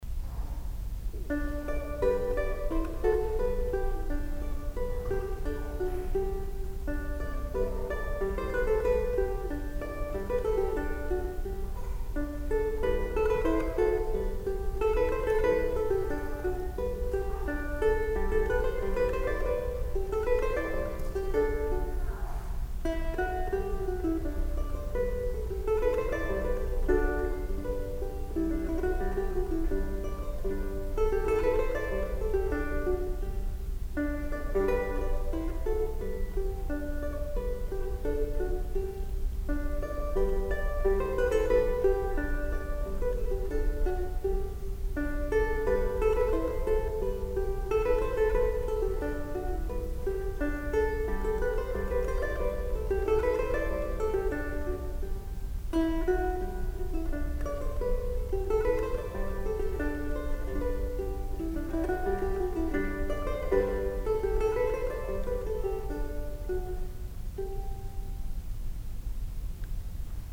on a Renaissance guitar.